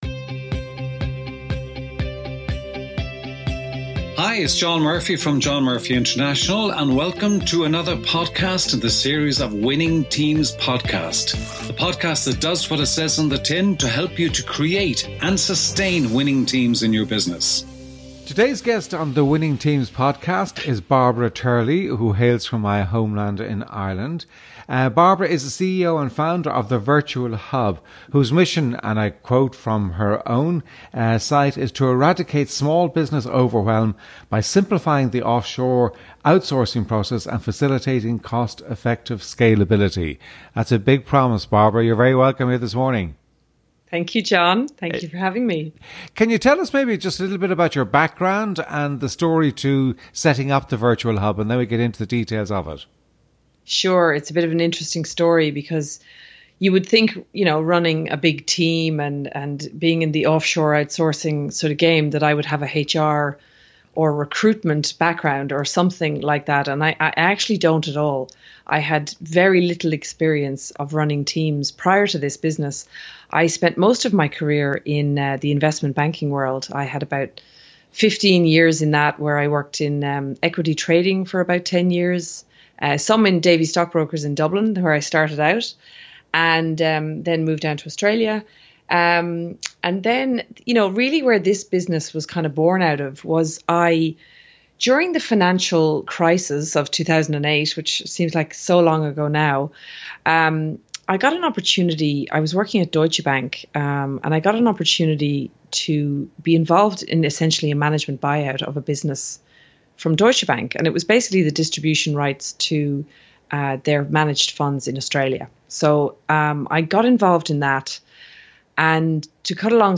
Introduction and Guest Background